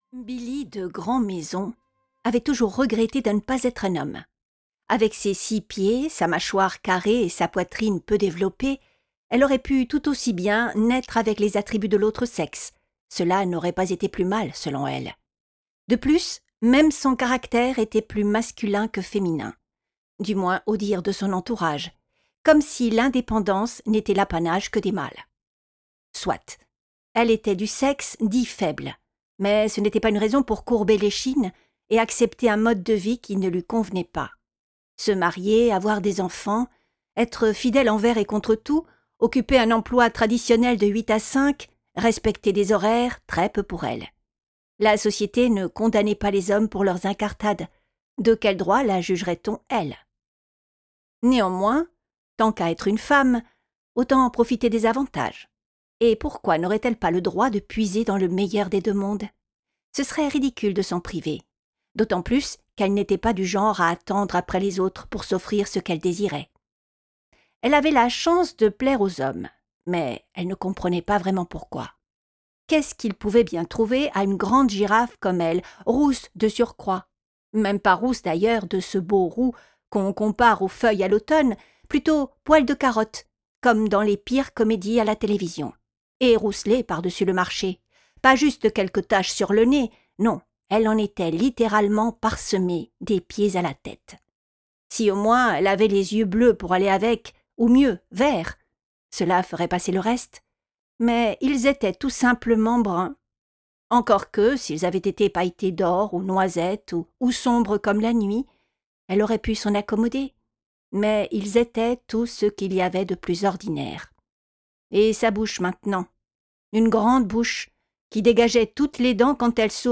Couverture du livre audio Billie, de Chantale Côté